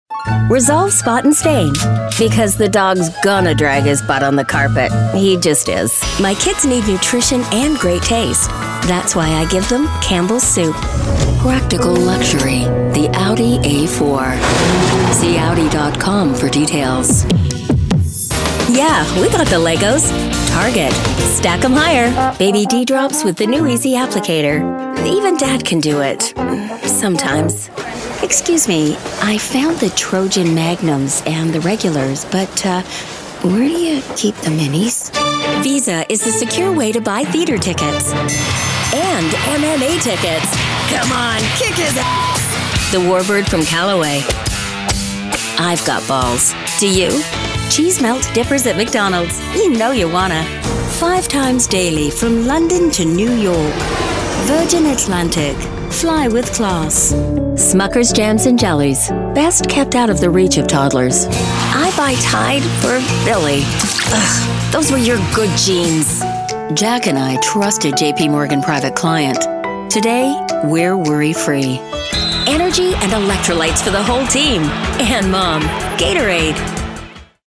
Female
Bright, Confident, Corporate, Friendly, Natural, Posh, Reassuring, Smooth, Soft, Warm, Versatile, Young, Approachable, Authoritative, Conversational, Energetic, Engaging, Upbeat
Canadian (native) neutral North American British RP
Audio equipment: professionally built booth / UR22mkII interface